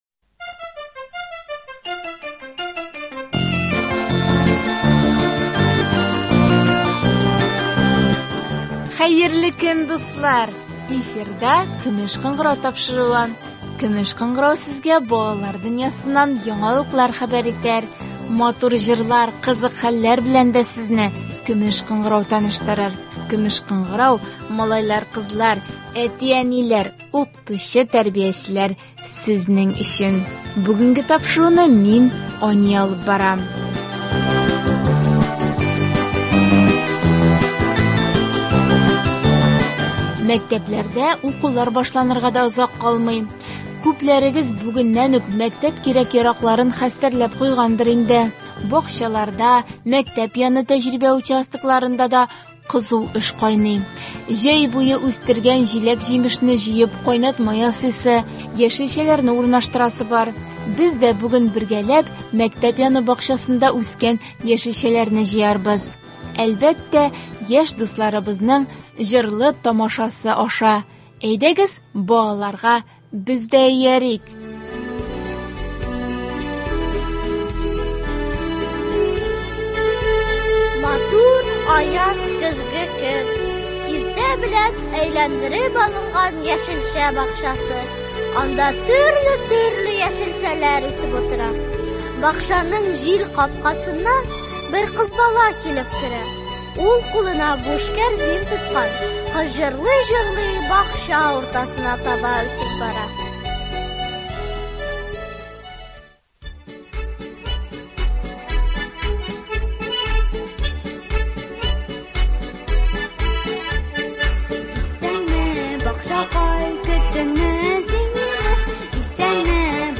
Без дә бүген бергәләп мәктәп яны бакчасында үскән яшелчәләрне җыярбыз. Әлбәттә , яшь дусларыбызның җырлы-тамашасы аша.